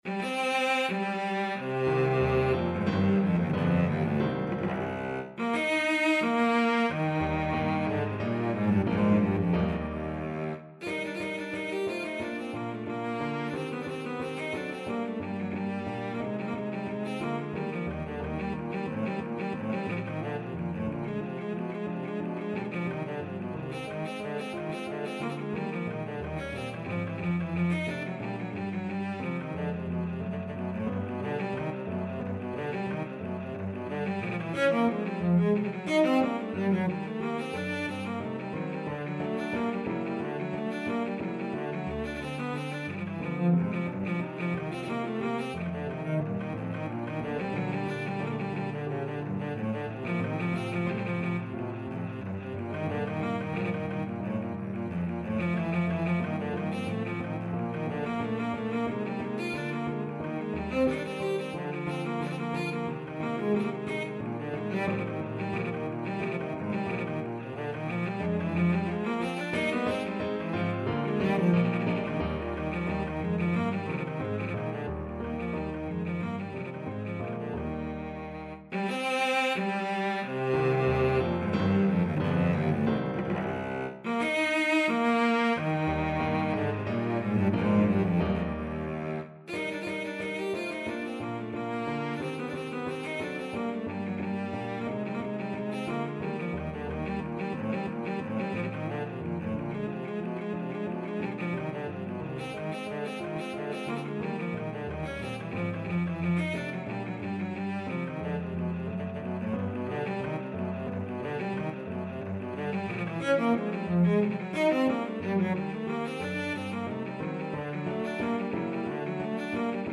Cello
C minor (Sounding Pitch) (View more C minor Music for Cello )
2. Allegro =90 (View more music marked Allegro)
2/4 (View more 2/4 Music)
C3-Ab5
Classical (View more Classical Cello Music)
tartini_devil_trill_2nd_mvt_VLC.mp3